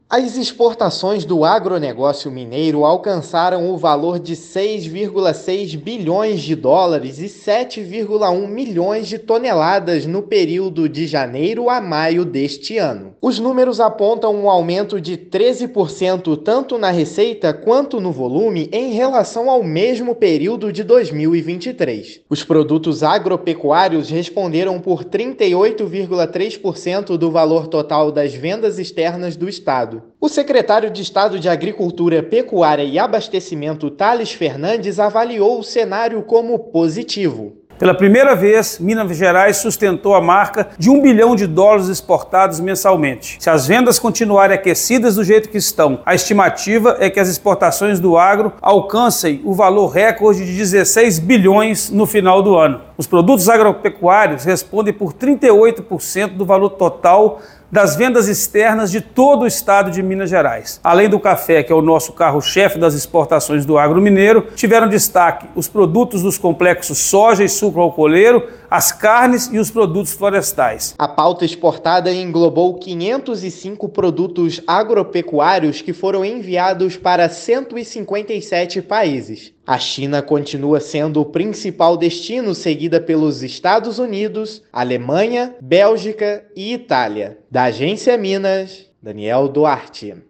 [RÁDIO] Exportações do agro mineiro atingem US$ 6,6 bilhões de janeiro a maio
Pela primeira vez na série histórica, Minas sustentou a marca de mais de US$ 1 bilhão exportado mensalmente nesse período. Ouça a matéria de rádio: